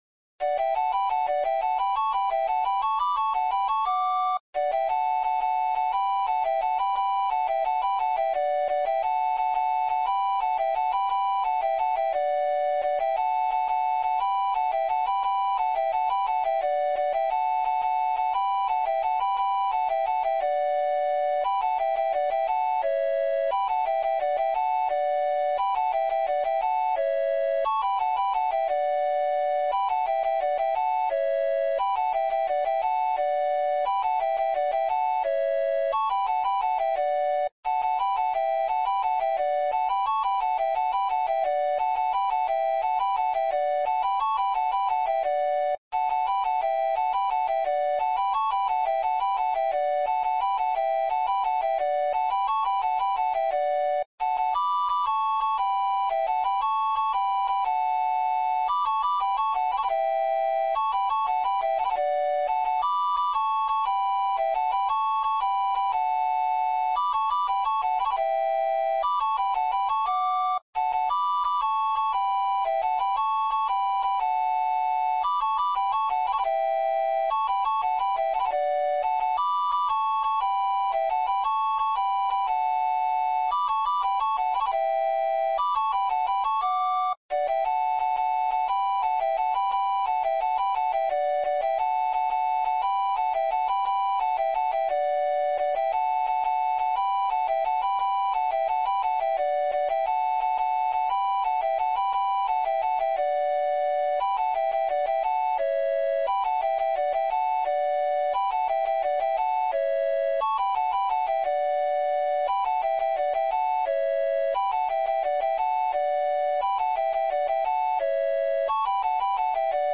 Muiñeiras
Dúo